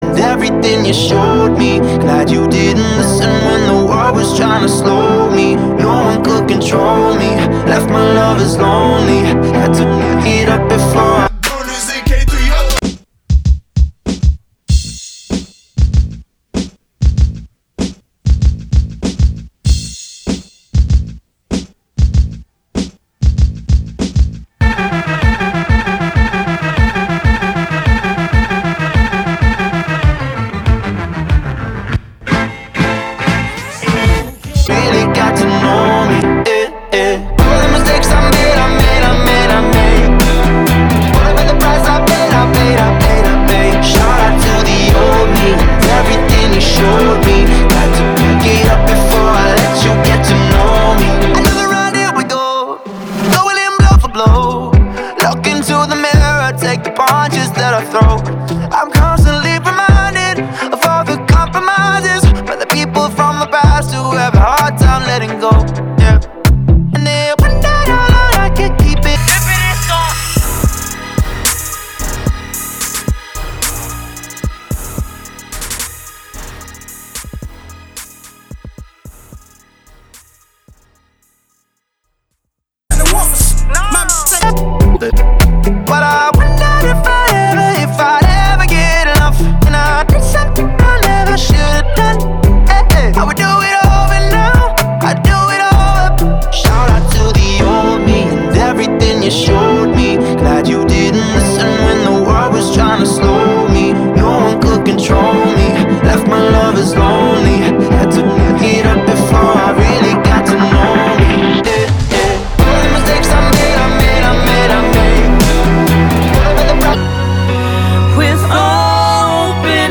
Acapellas